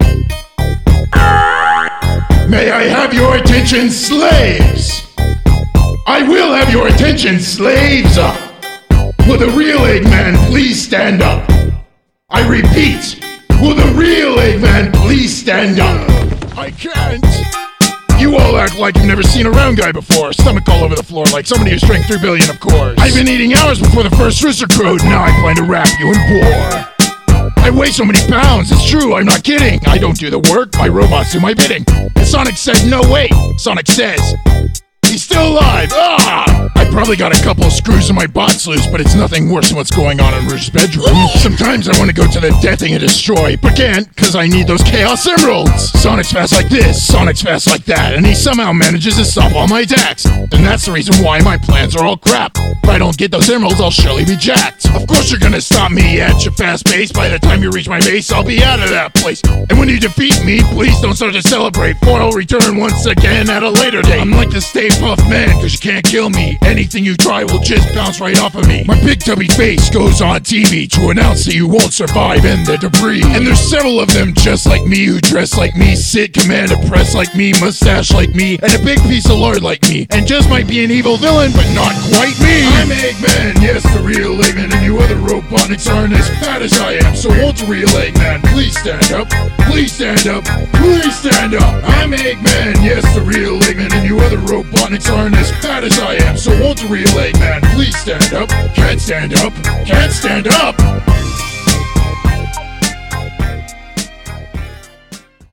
song parodies